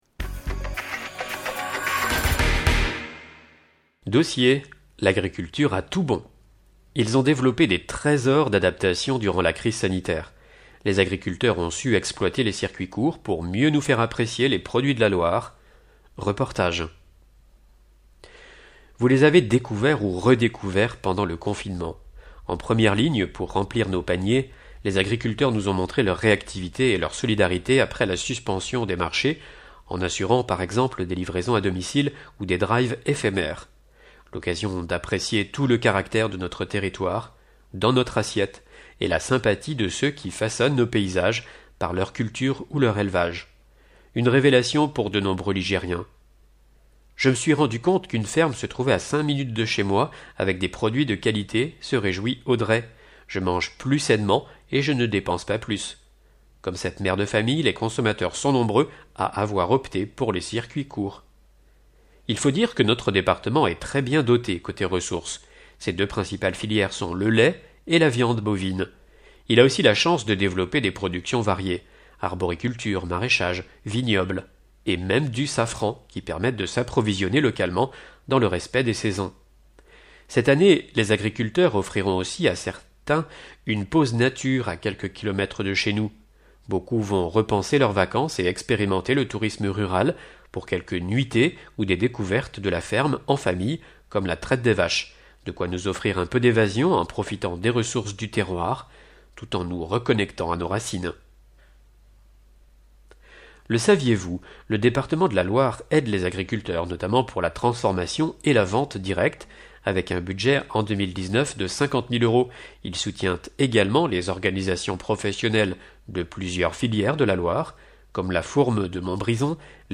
Loire Magazine n°142 version sonore